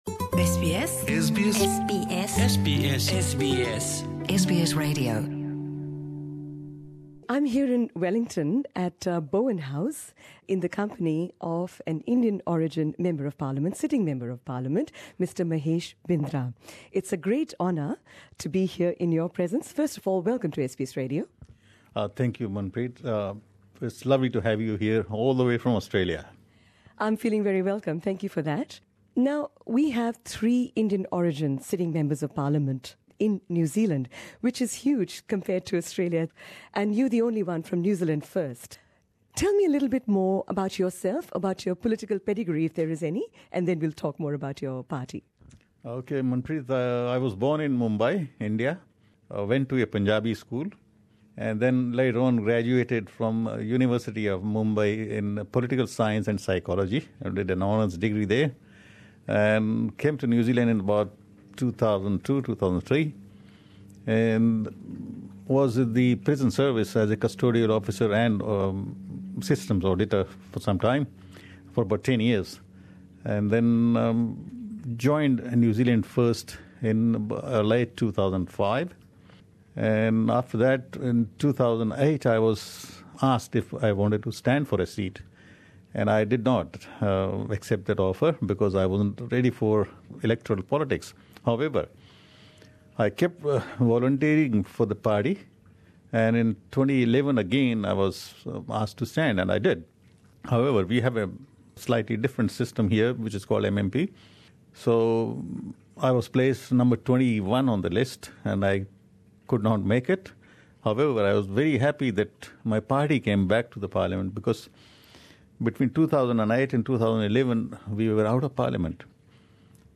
Here is SBS Punjabi's interview with him in English, in which he spoke about issues faced by international students in New Zealand, about the perception that his party is anti-immigration or racist, and also a message to our listeners in Australia Share